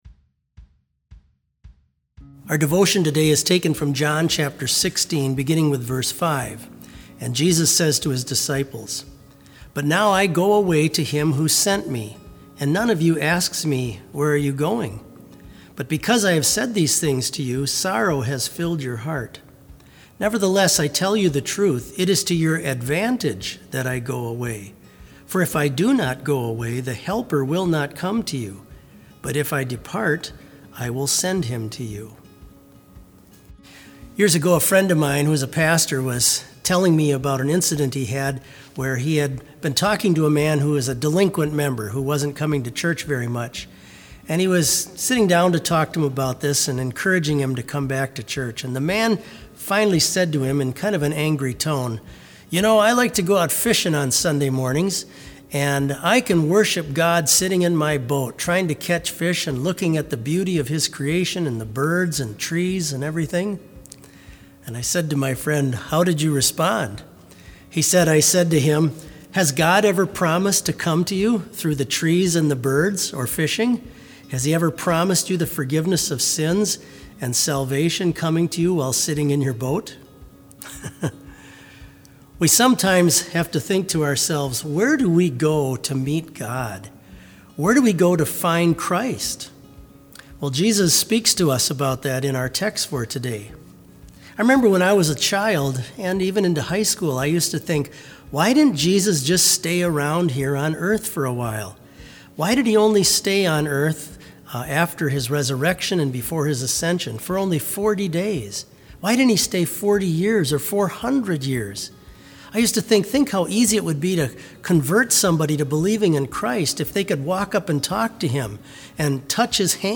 Complete service audio for BLC Devotion - May 6, 2020